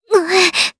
Leo-Vox_Damage_jp_01.wav